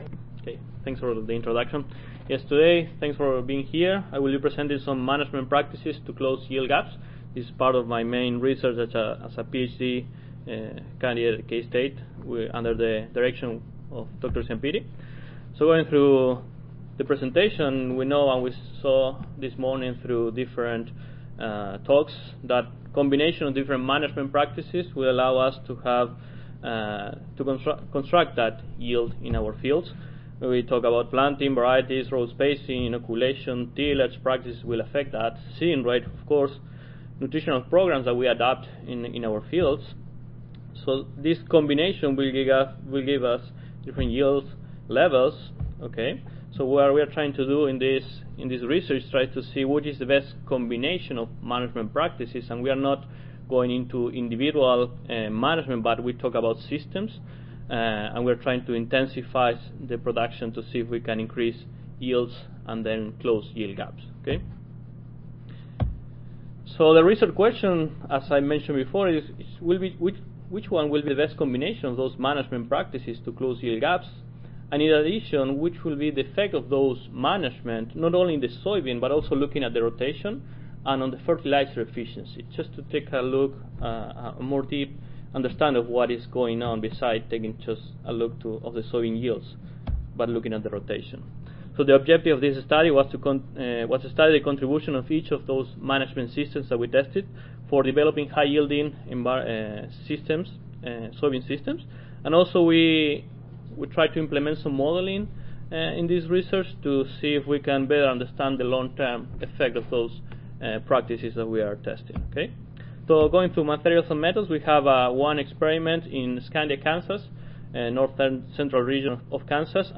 See more from this Division: ASA Section: Agronomic Production Systems See more from this Session: Applied Soybean Research (includes student competition)